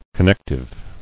[con·nec·tive]